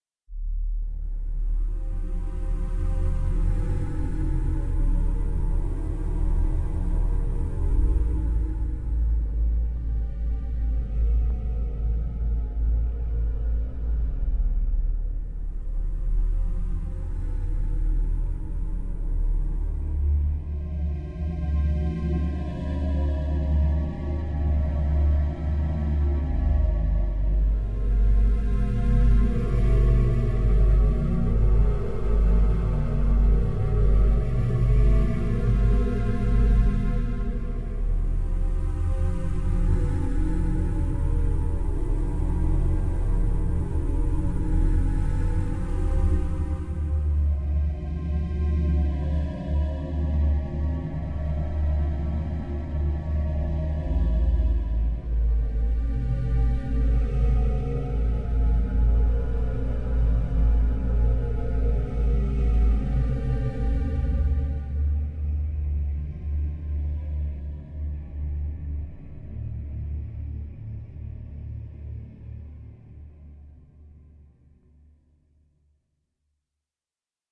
Walking in unknown places | Sound effect .mp3 | Download free.